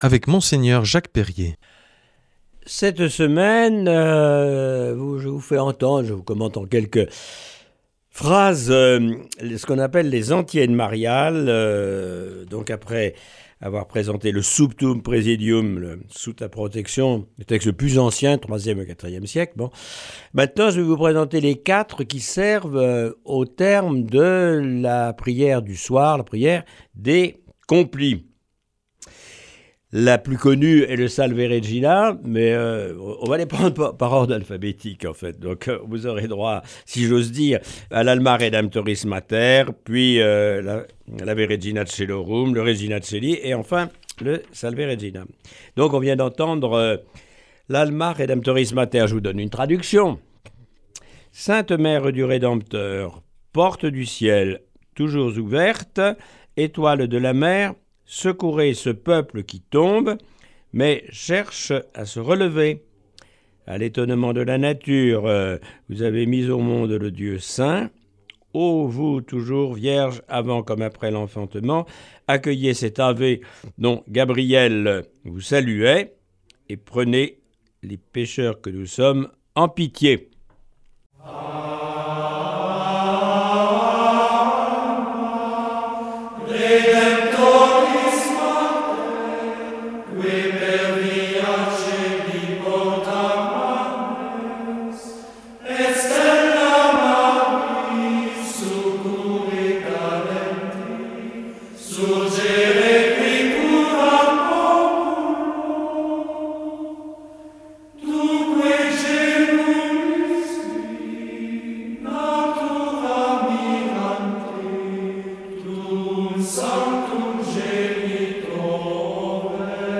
Cette semaine, Mgr Jacques Perrier nous propose des méditations sur des antiennes mariales.